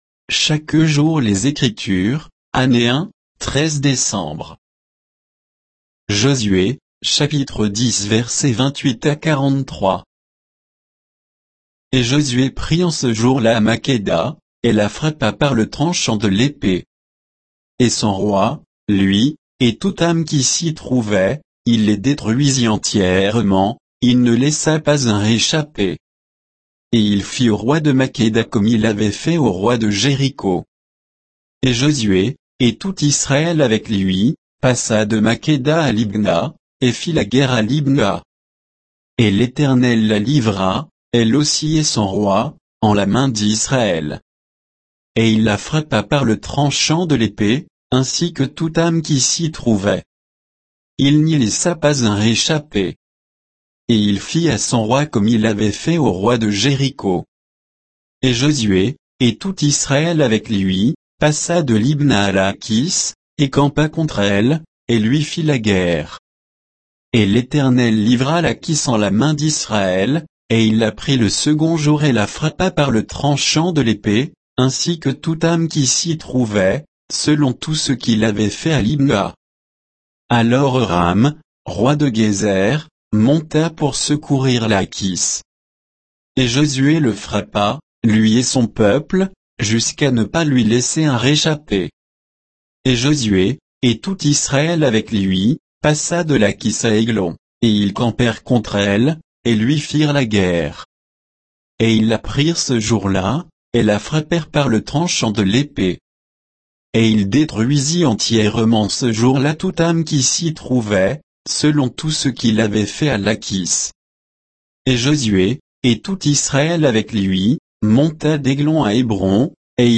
Méditation quoditienne de Chaque jour les Écritures sur Josué 10, 28 à 43